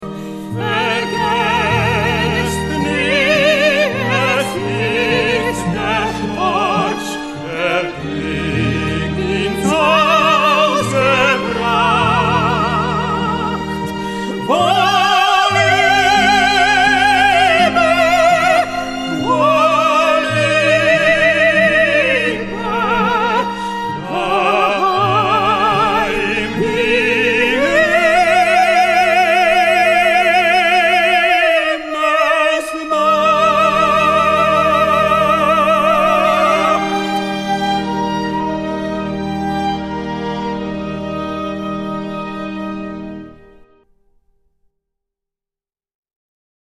The CD contains both vocal and orchestral pieces.